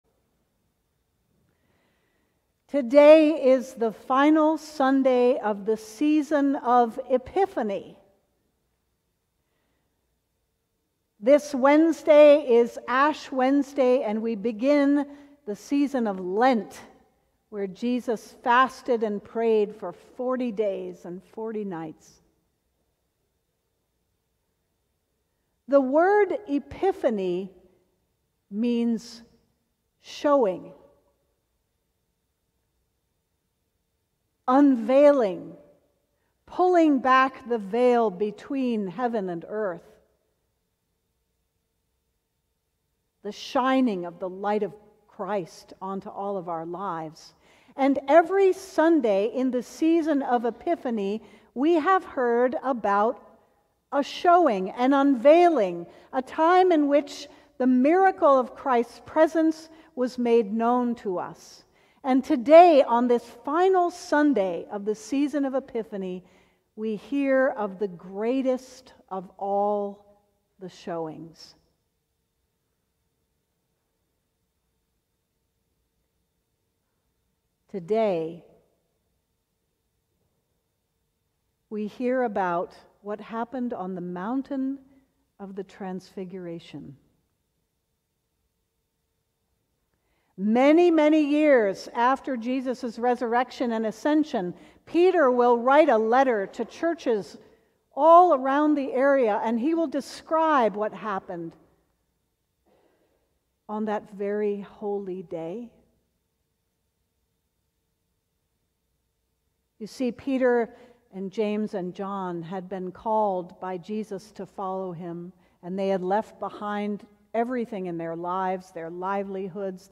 Sermon: Plug into the Source - St. John's Cathedral